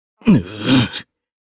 Kain-Vox_Damage_jp_02.wav